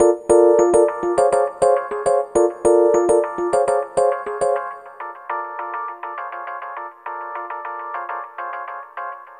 Ala Brzl 1 Fnky Piano-G.wav